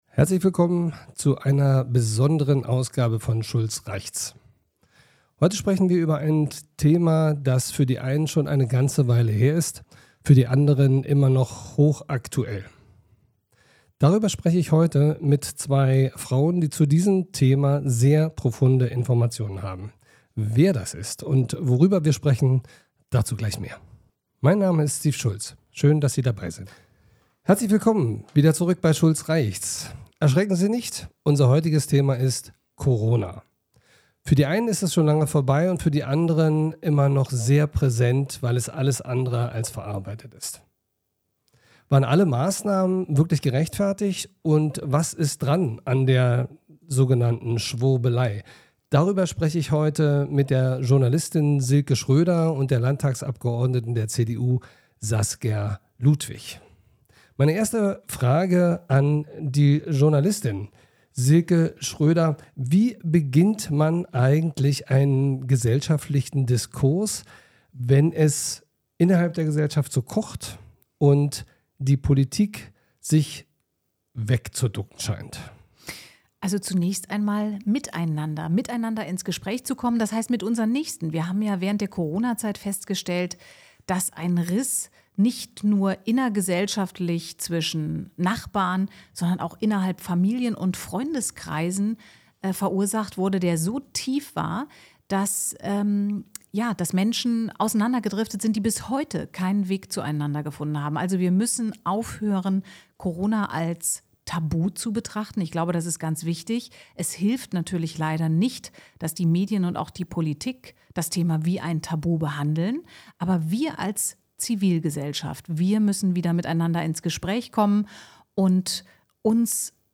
Thema: Corona-Aufarbeitung, im Gespräch dazu Journalistin
Saskia Ludwig, Abgeordnete im Landtag Brandenburg.